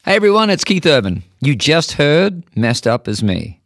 LINER Keith Urban (Messed Up As Me) 6